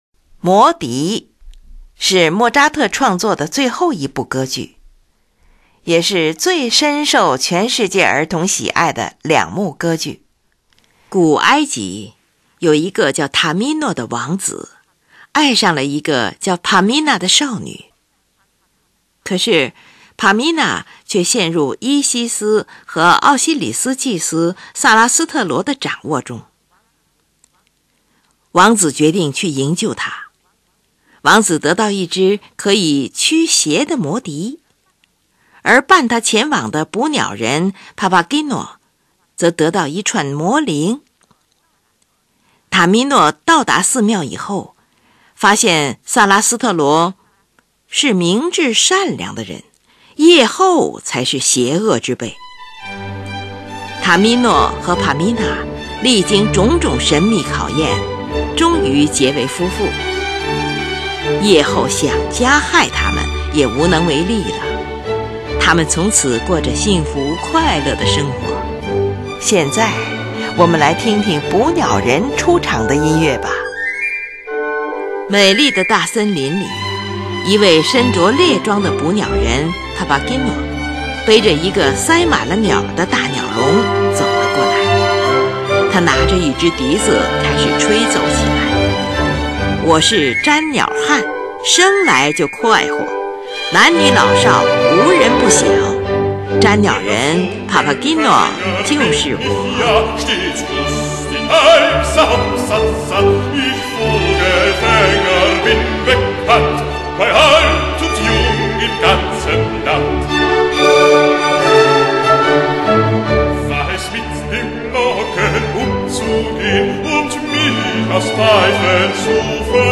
歌剧，大歌剧，喜歌剧，二重唱。